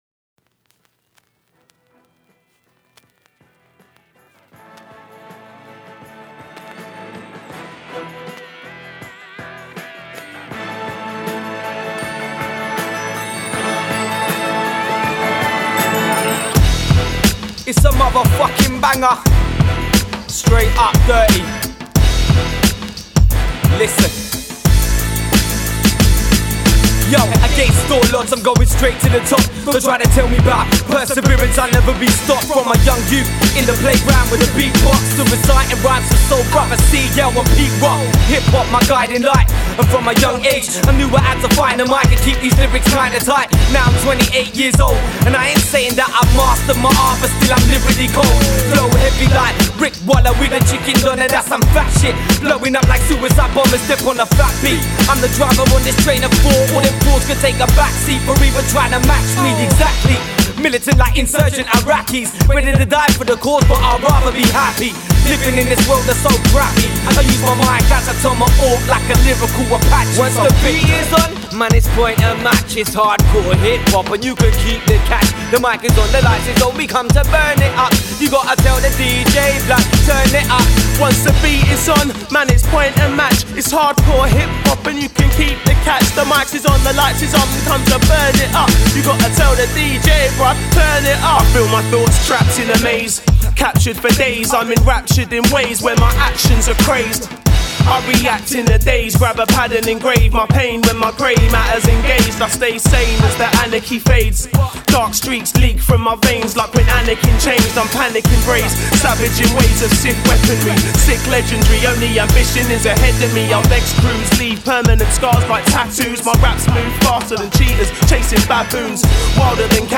3 Styl: Hip-Hop Rok